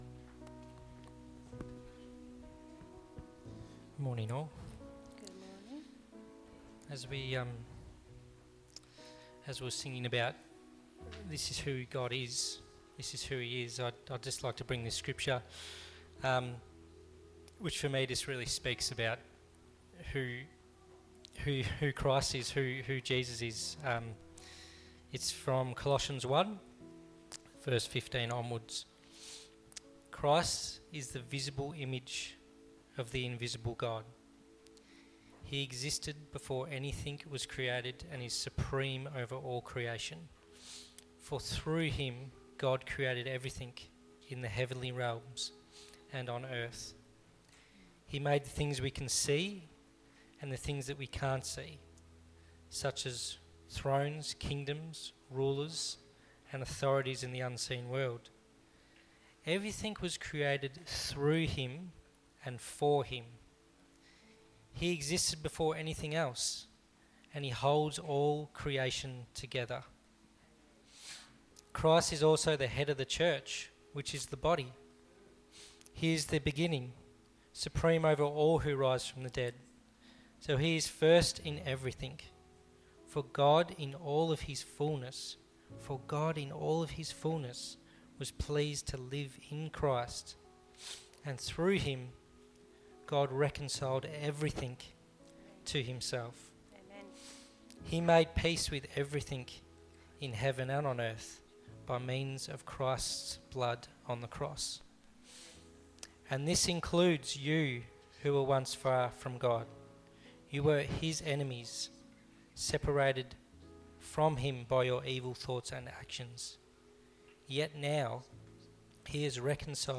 Sunday Message